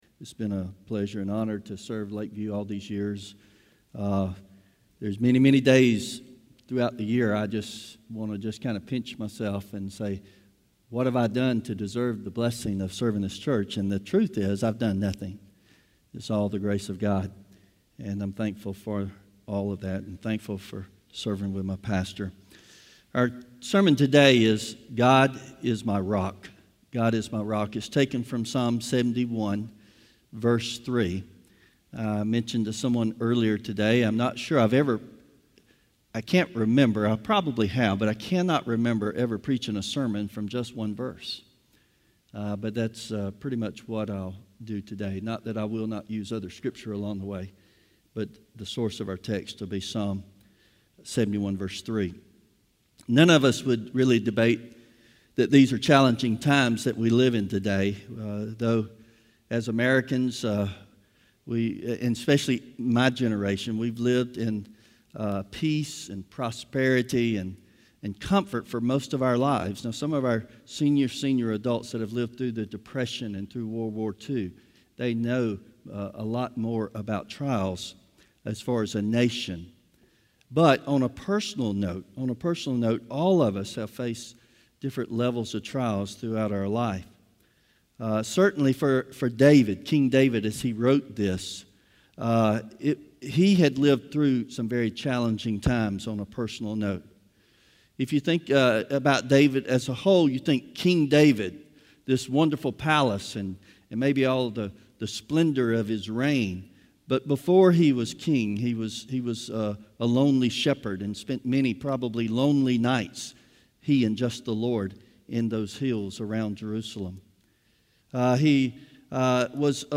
Psalm 71:3 Service Type: Sunday Morning God is our Rock and Salvation